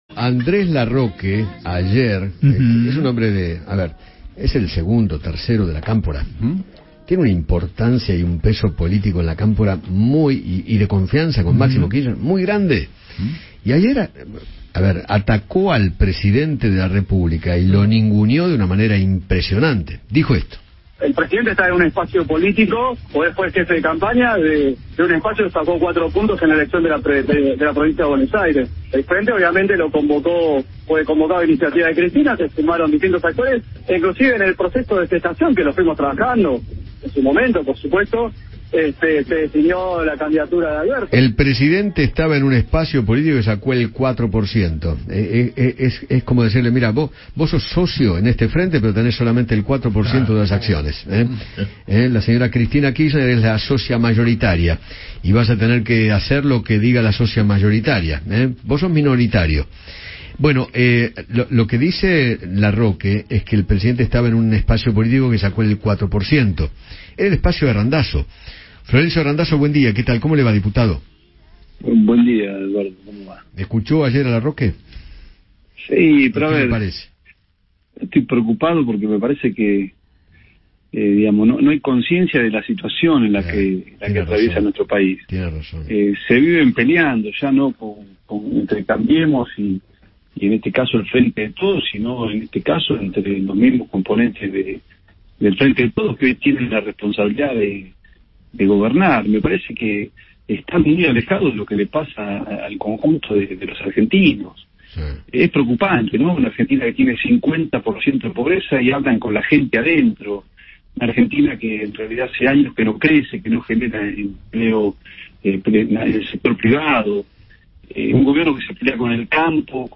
Florencio Randazzo, diputado nacional, habló con Eduardo Feinmann sobre las críticas de Andrés Larroque contra Alberto Fernandez y sostuvo que existe un “divorcio entre la dirigencia política y la sociedad”.